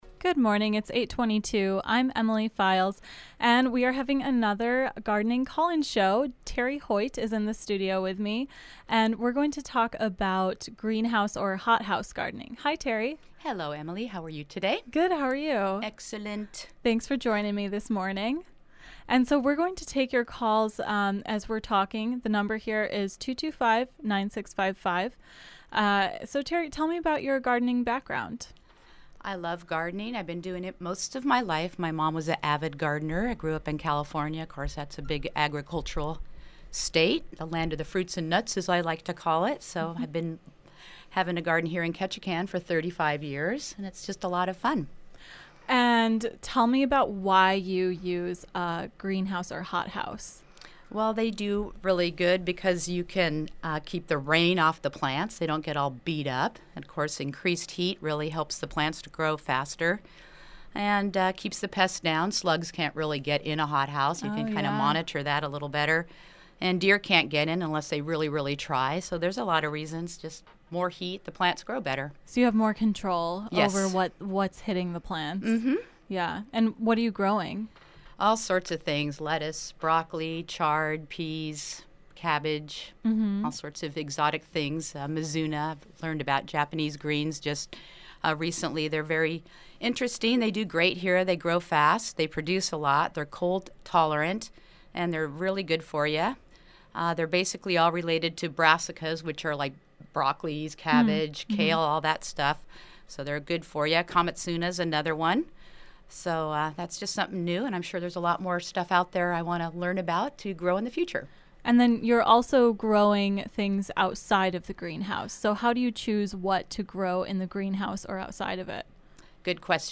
Listen to the call-in conversation here: